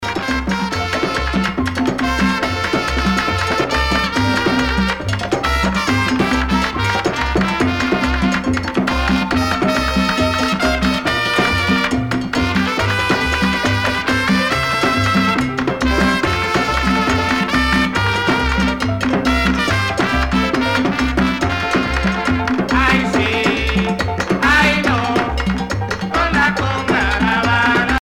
danse : conga
Pièce musicale éditée